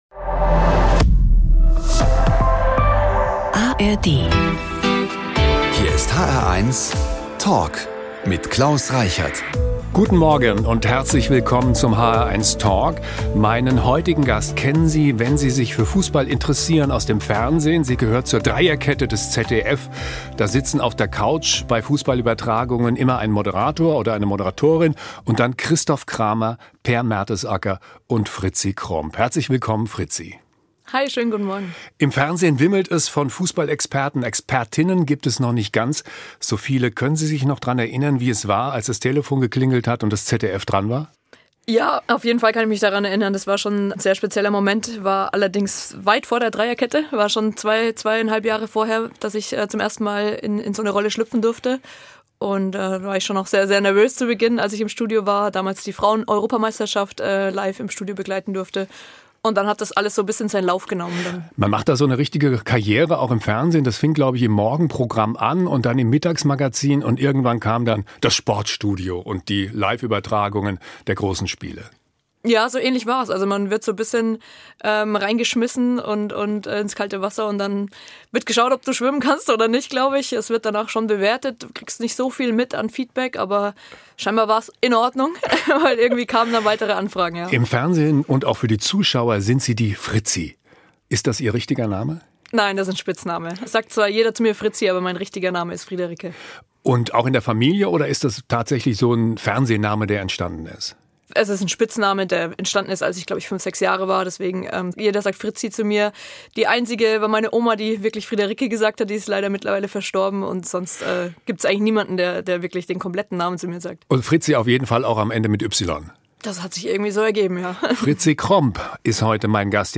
Überraschende Einblicke und spannende Ansichten: Die hr1-Moderatoren im sehr persönlichen Gespräch mit Prominenten.